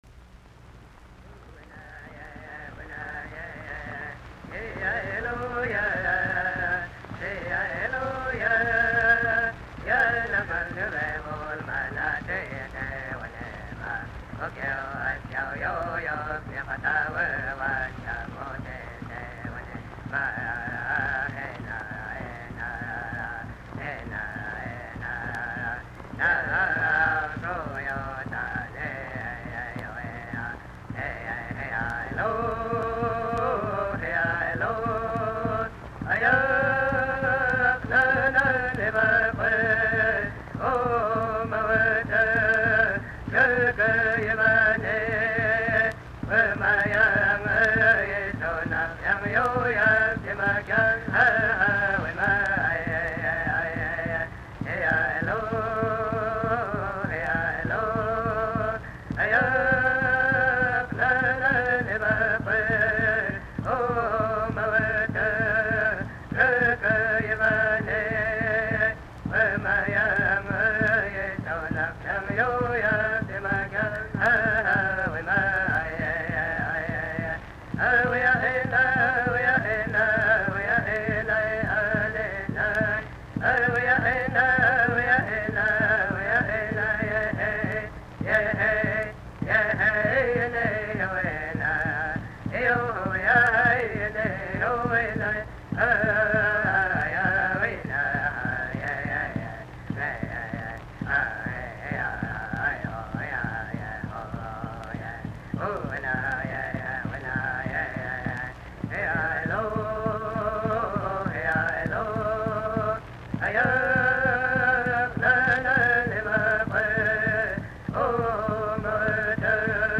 Recorded in Indian communities by Willard Rhodes, with the cooperation of the United States Office of Indian Affairs.